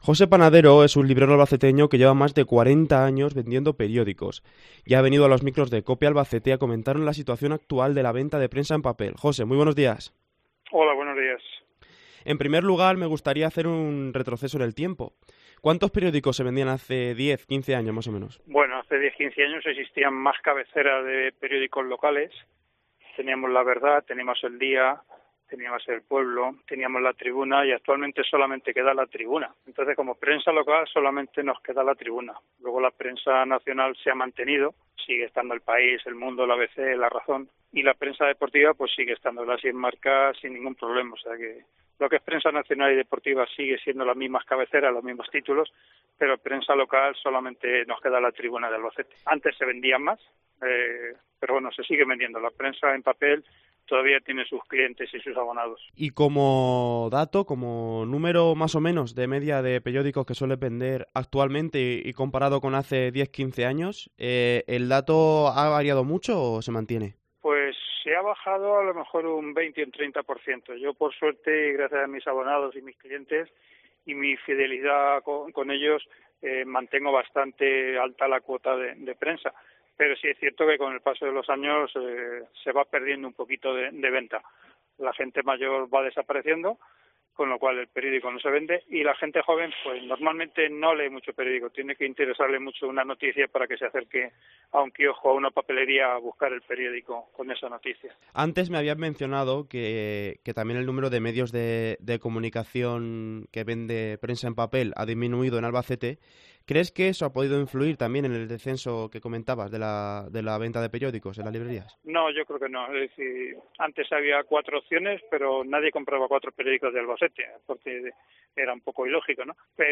Este quiosquero cuenta con un amplísimo bagaje al frente de una librería-papelería situada en el barrio de San Antón.